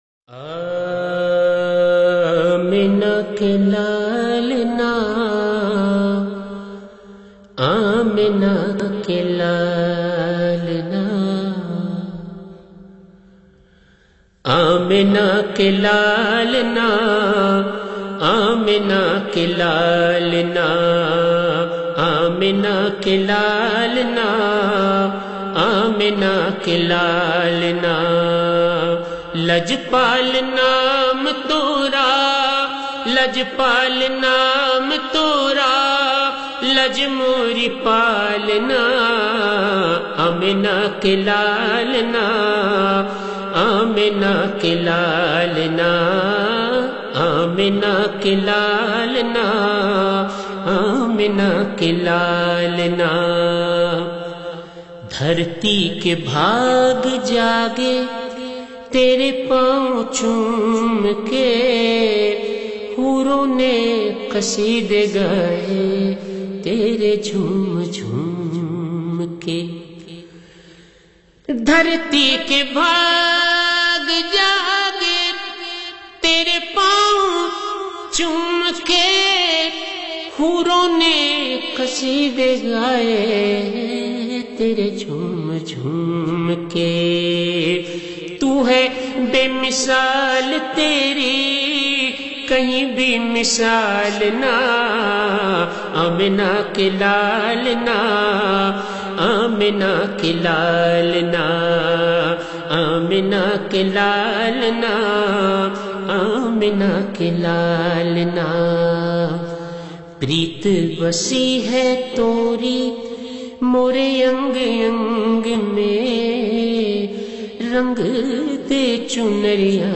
Heart Touching Naat
naat khuwani
Known for his melodious voice and emotive delivery